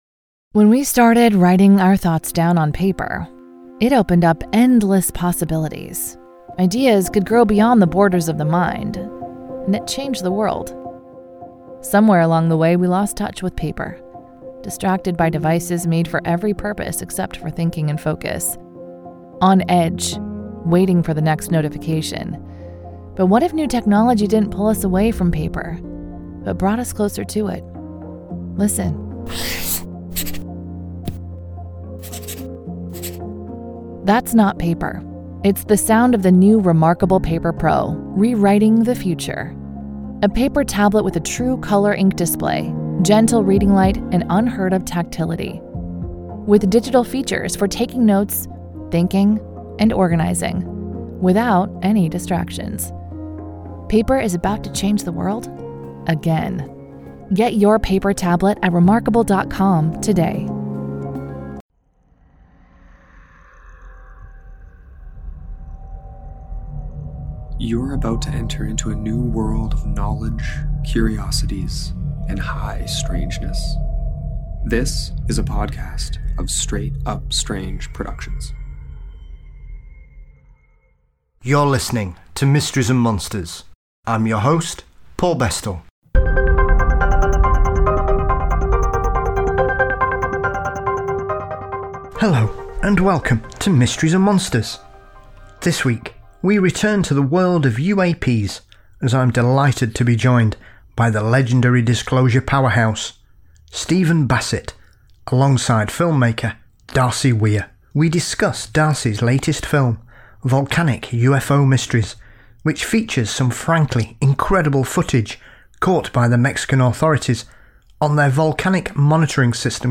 It's a thought provoking conversation which I hope you enjoy.